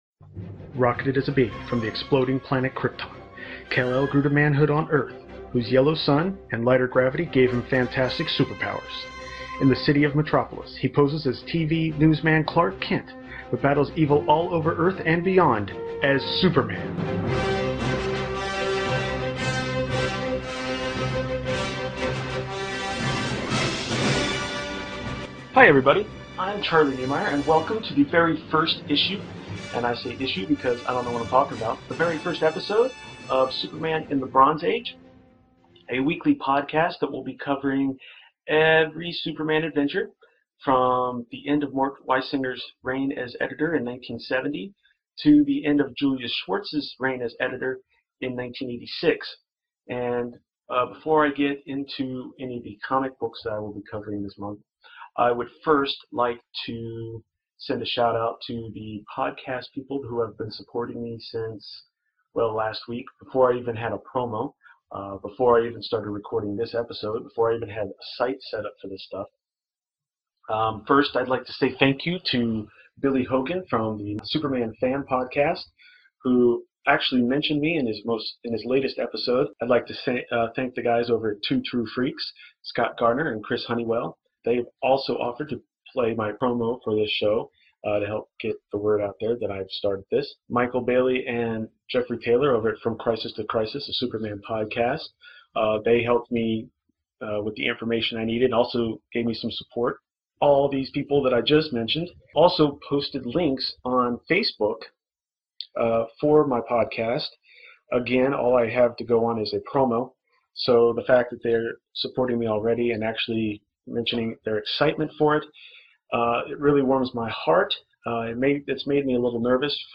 My apologies for some of the audio problems.